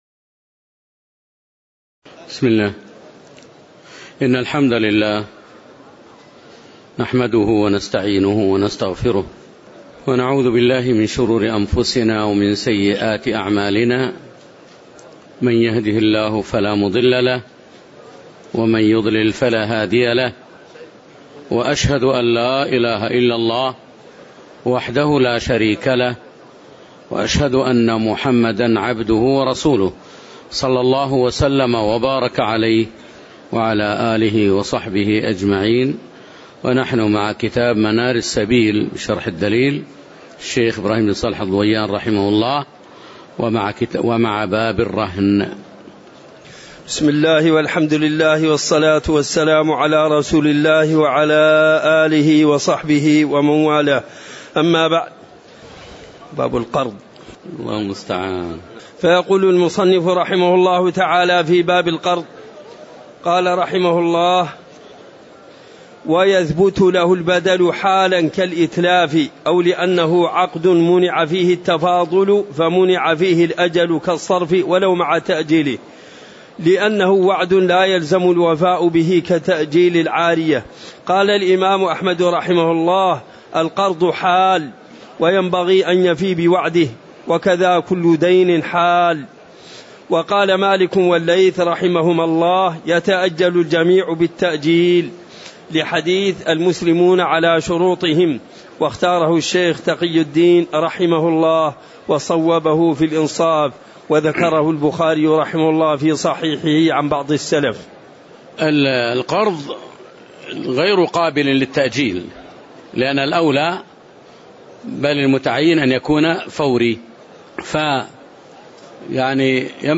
تاريخ النشر ٤ جمادى الأولى ١٤٤٠ هـ المكان: المسجد النبوي الشيخ